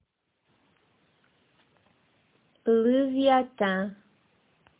pronunciation); Monstro